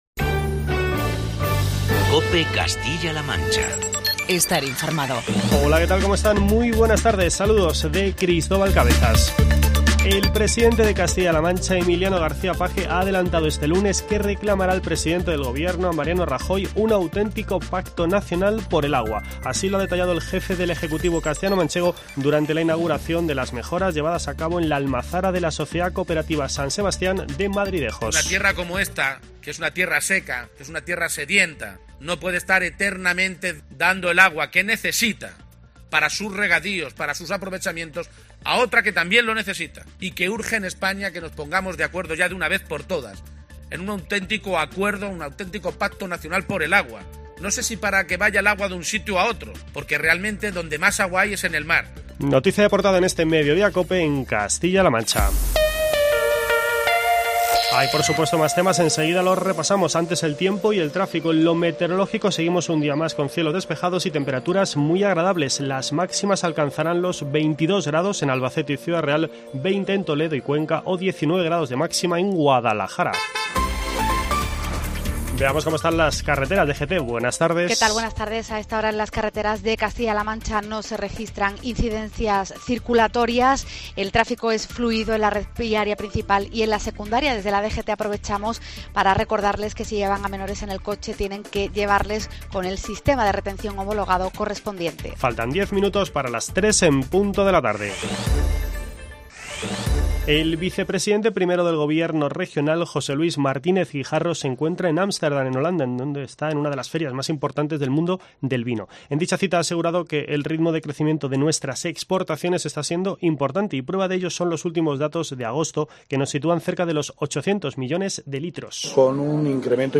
13:00 H | 22 NOV 2025 | BOLETÍN